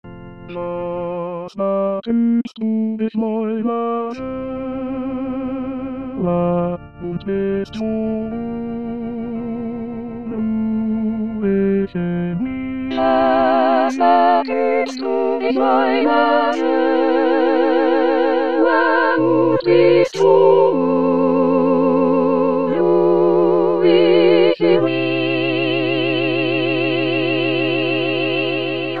Introduction 5 voix, mes.  1-13
Tutti